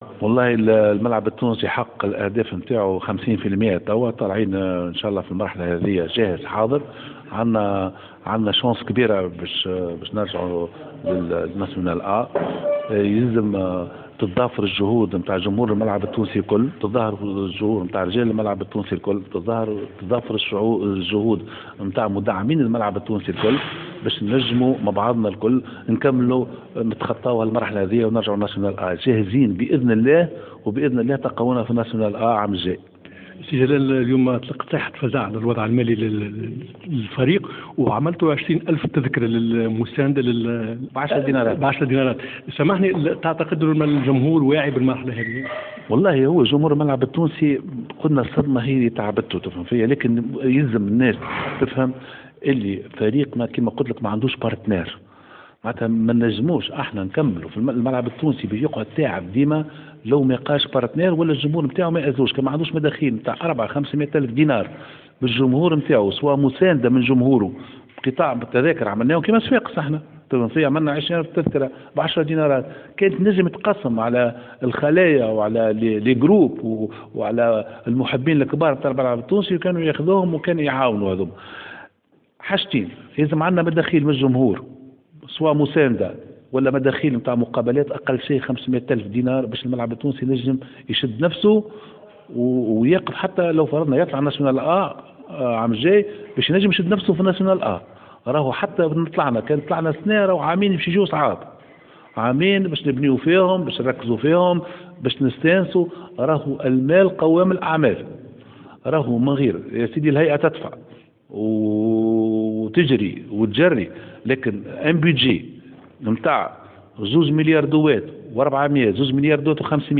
ندوة صحفية